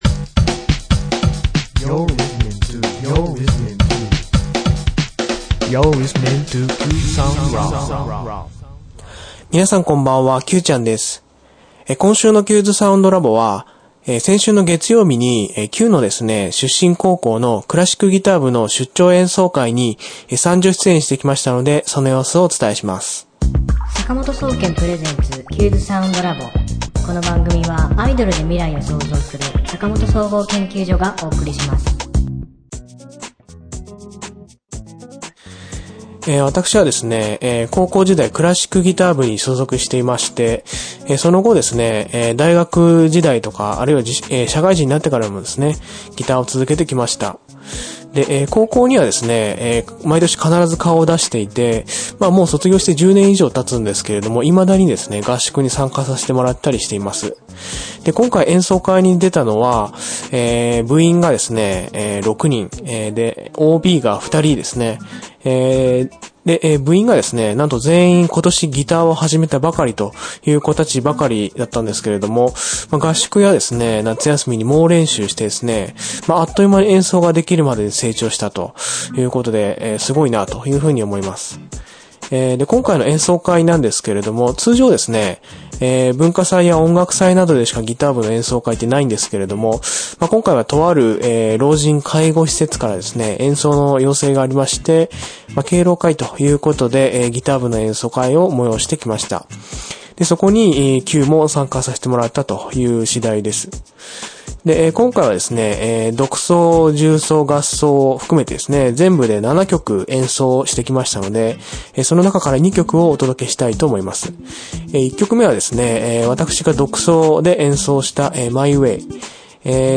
今週のテーマ：高校のギター部の演奏会に参加してきました！
クラシックギターですよ。 独奏、重奏、合奏を全部で７曲演奏しましたが、その中から２曲を演奏会の様子と共にお届けしたいと思います。